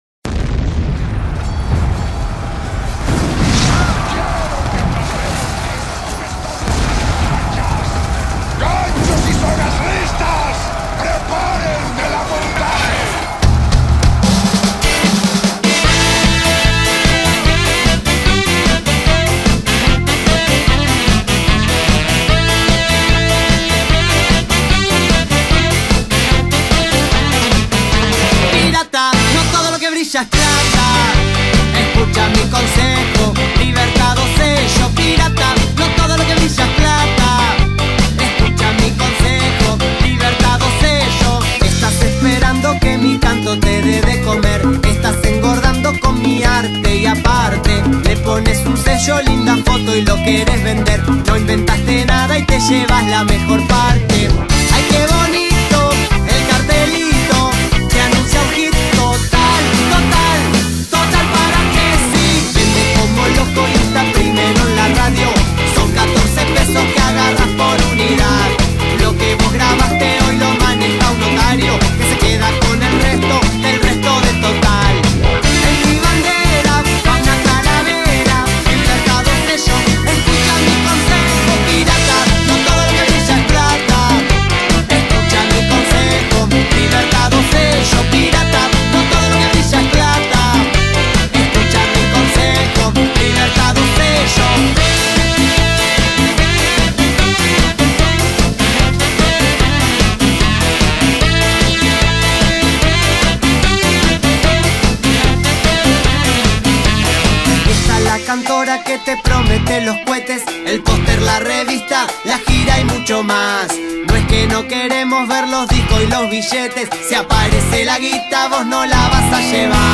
Carpeta: Rock uruguayo mp3